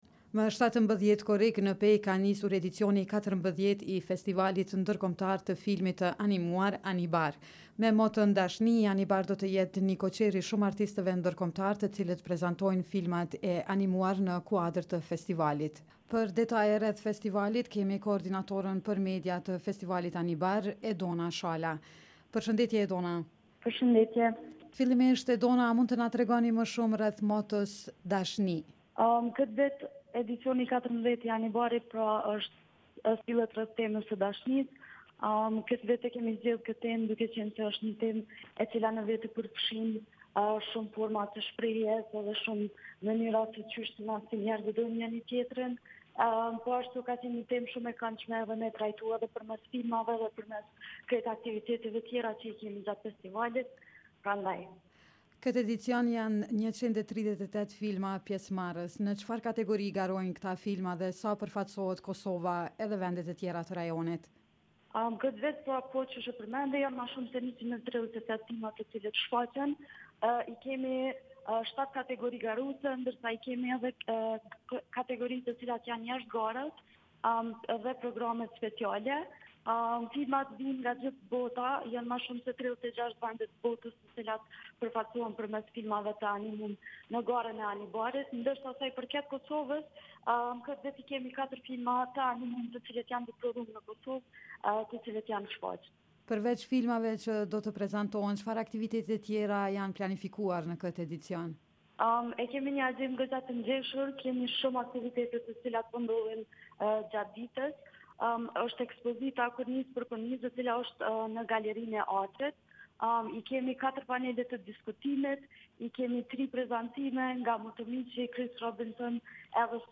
Në një bisedë me Radion Evropa e Lirë